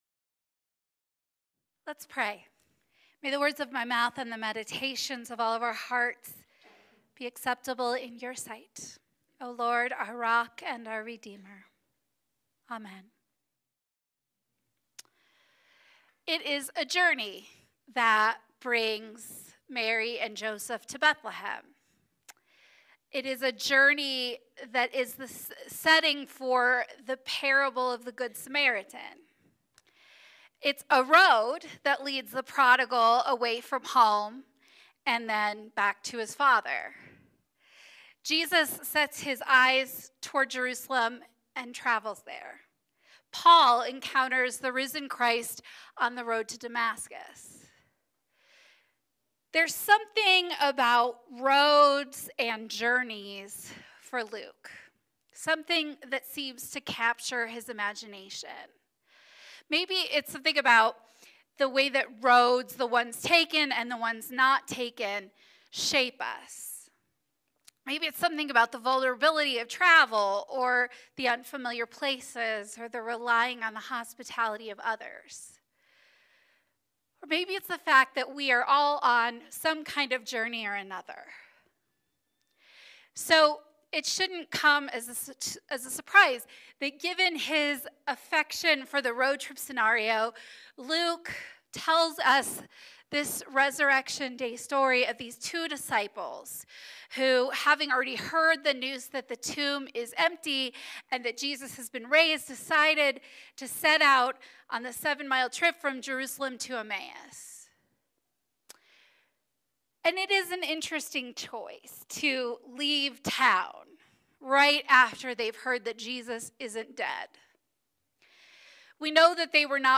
Sermons | United Methodist Church of Evergreen